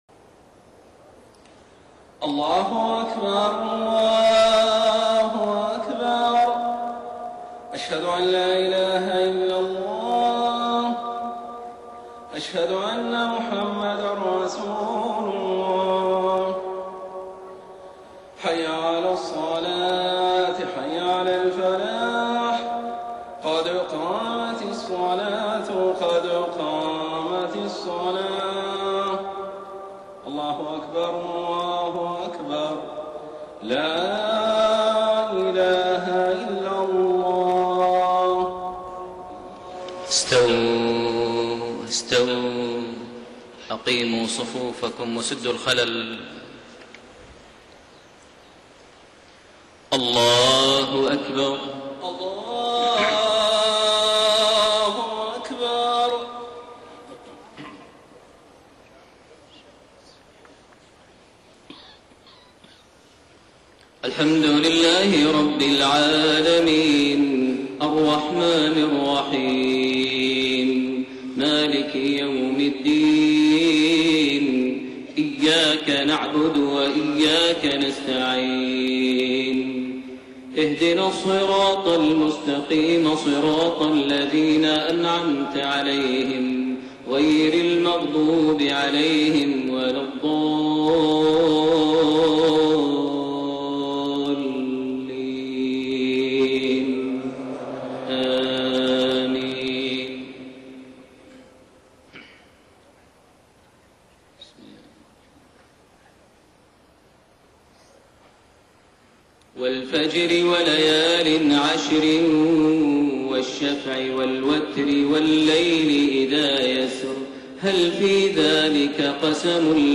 صلاة المغرب 28 جمادى الآخرة 1433هـ سورة الفجر > 1433 هـ > الفروض - تلاوات ماهر المعيقلي